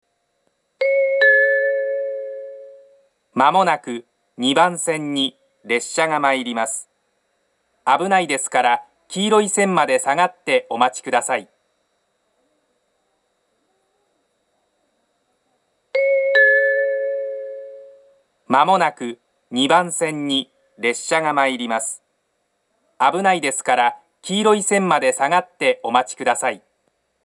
接近放送の流れるタイミングは早くなく、接近表示機が点滅してから録音をはじめても十分に間に合います。
その後すぐに設定を変更したようで、２０１９年３月下旬には上下で放送の男女が入れ替わり、言い回しも変更されています。
２番線接近放送 下り本線です。
minami-sendai-2bannsenn-sekkinn1.mp3